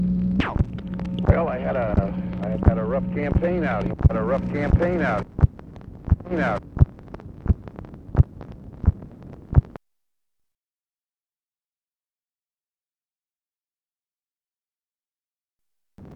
Conversation with EDMUND G. BROWN, June 13, 1966